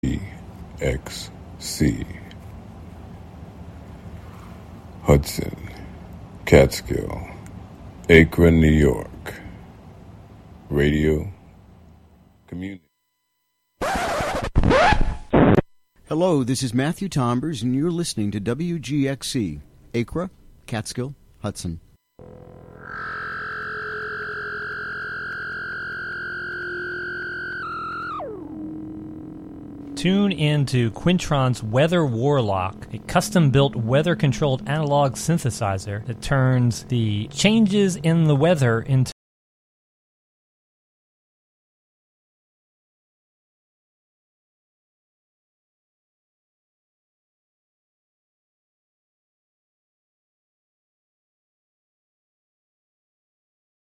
Tune in for radio art, radio theatre, experiments, live shows, and more.